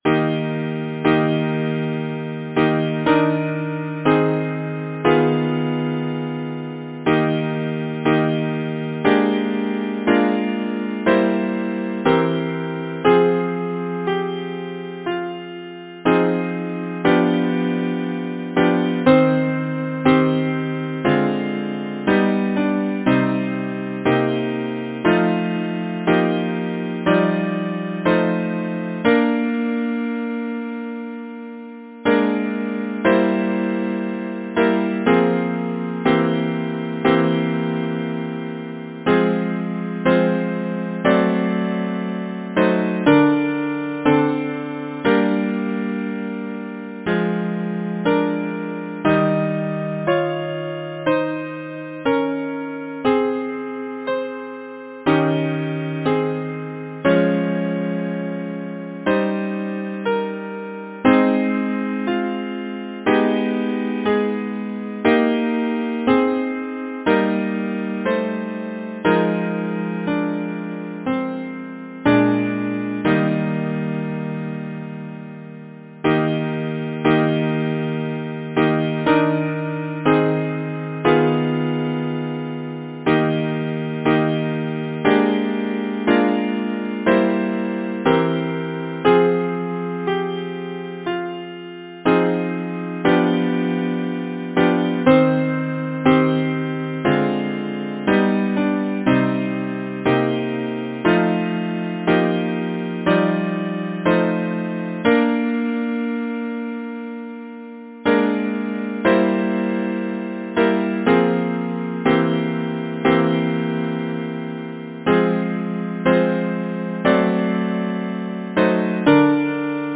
Title: While you sleep Composer: Henry Kimball Hadley Lyricist: Lucy Lane Clifford Number of voices: 4vv Voicing: SATB Genre: Secular, Partsong
Language: English Instruments: A cappella